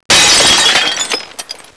Crasssssh!
glassbreak.wav